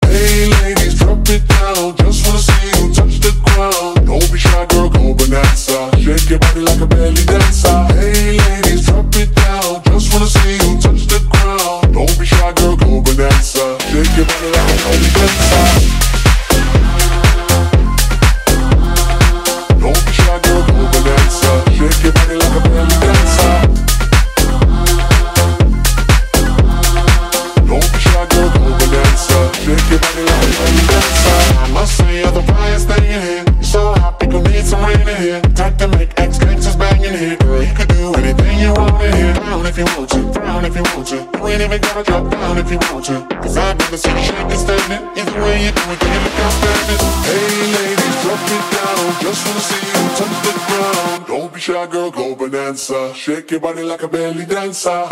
Kategorien: POP